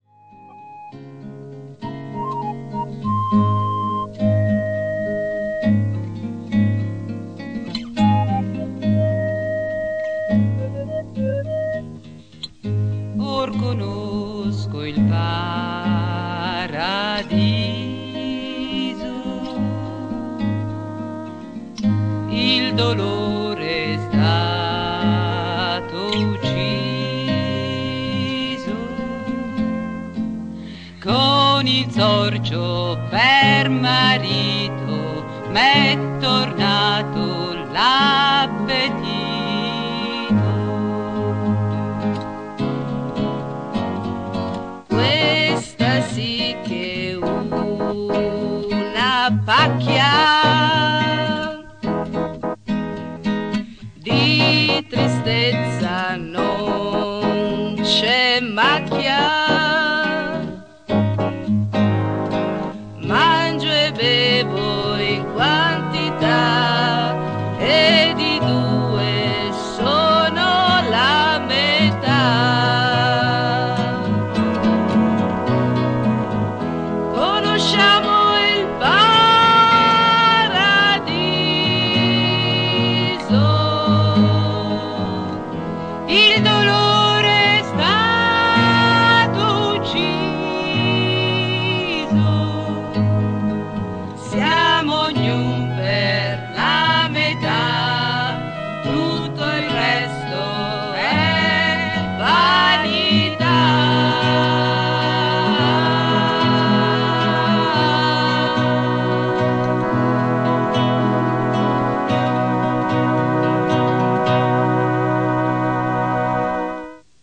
Musiche di Giovanni Iaffaldano
FORMICHINA     (Abbracciata al sorcio, alla finestra cantano: Conosco il paradiso)